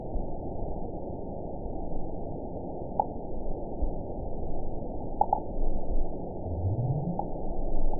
event 912275 date 03/23/22 time 12:41:10 GMT (3 years, 1 month ago) score 9.67 location TSS-AB03 detected by nrw target species NRW annotations +NRW Spectrogram: Frequency (kHz) vs. Time (s) audio not available .wav